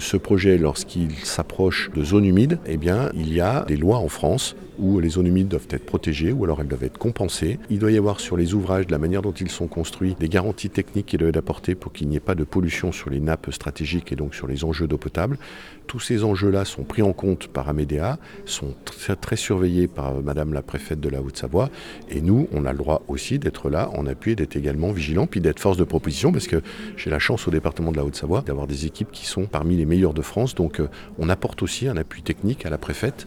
L’A12 est vivement critiquée pour son impact environnemental et à ce sujet Martial Saddier se veut rassurant, notamment sur la question de l’eau et des zones humides.